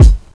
BABY BUBBA Kick.wav